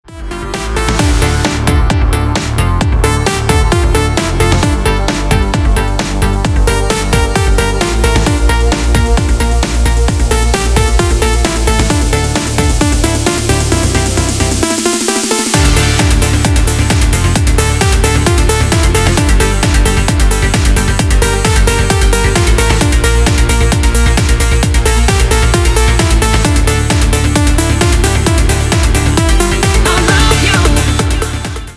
• Качество: 320, Stereo
громкие
женский вокал
зажигательные
Electronic
EDM
Trance
Стиль: trance